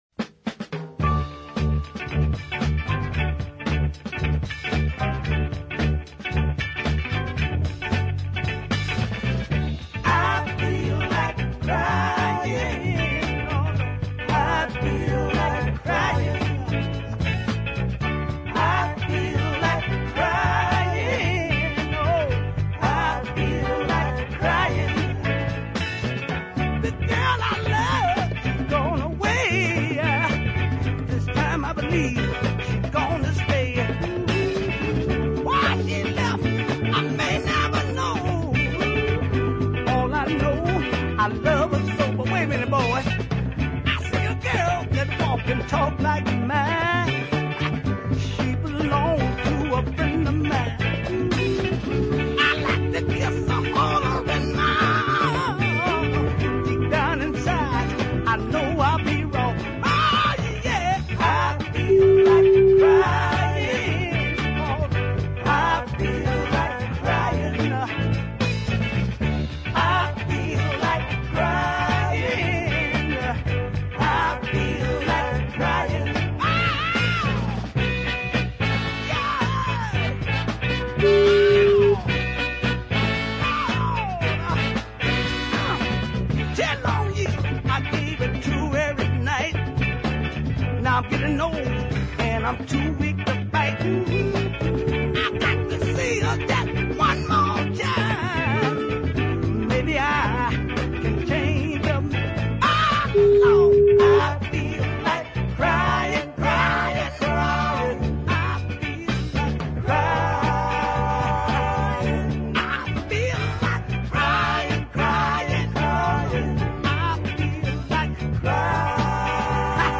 easy paced numbers